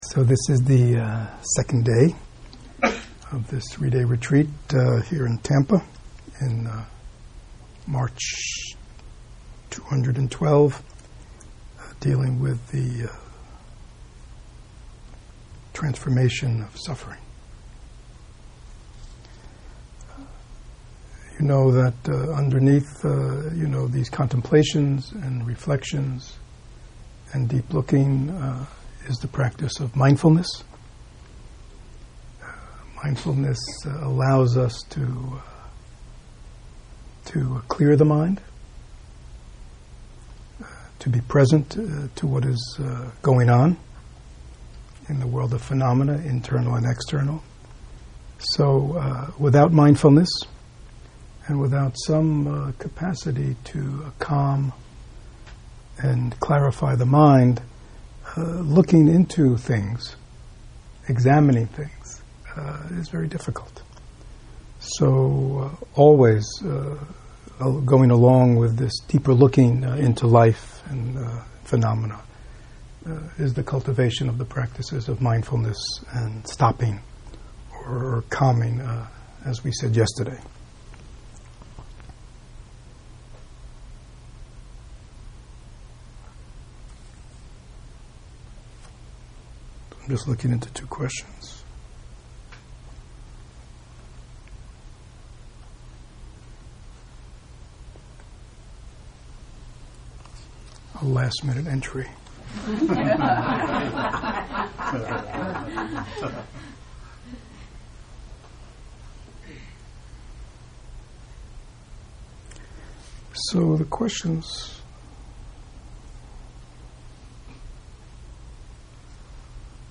This three day retreat was offered for students wanting to make a commitment to their spiritual development by looking deeply into the Four Noble Truths as a guide to transforming suffering. It offered participants a personal, practical and targeted approach to working with their suffering as an integral part of daily practice.